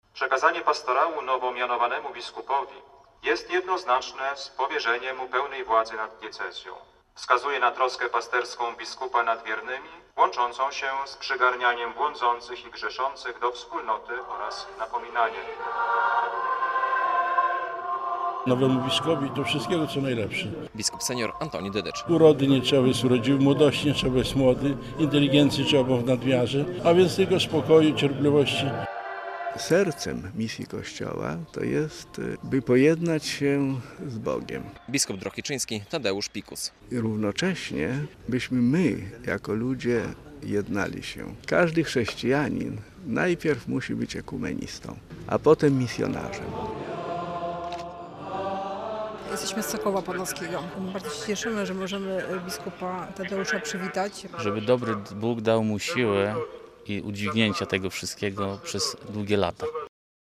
Ingres biskupa Tadeusza Pikusa w Drohiczynie - relacja